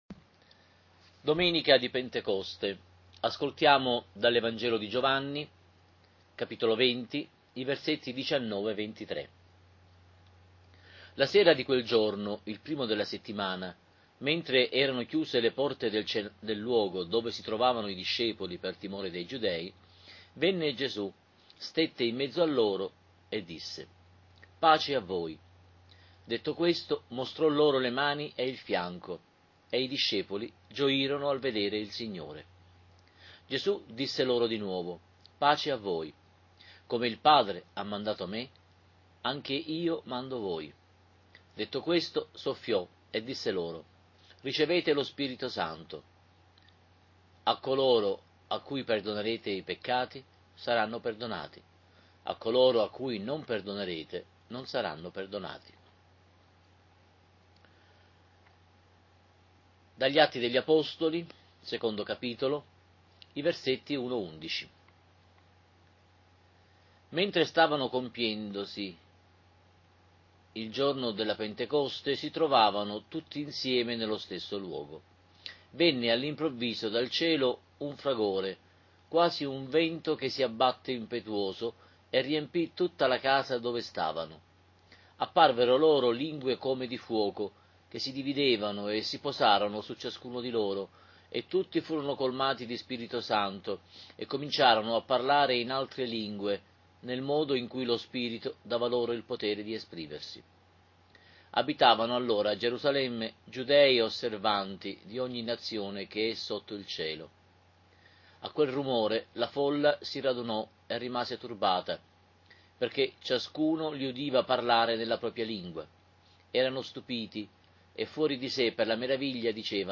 Lectio divina della Domenica di PENTECOSTE, A - Abbazia di Pulsano. Sito ufficiale